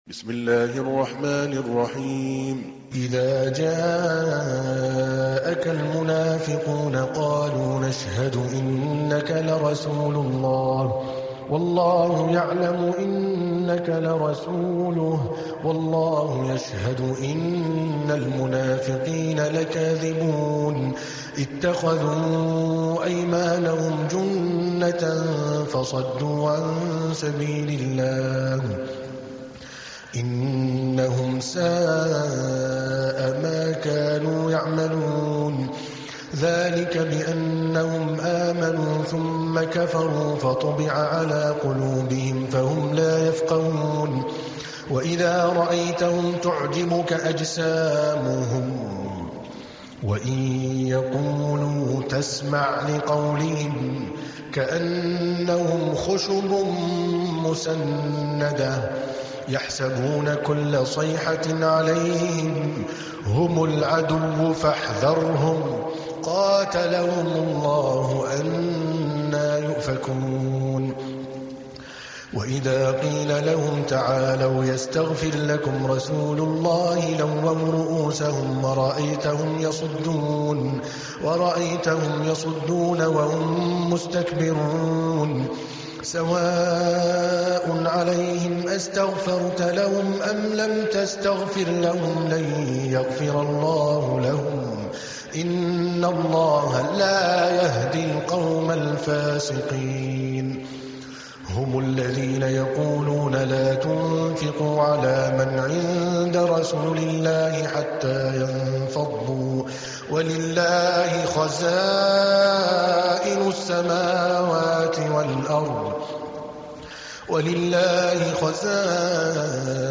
تحميل : 63. سورة المنافقون / القارئ عادل الكلباني / القرآن الكريم / موقع يا حسين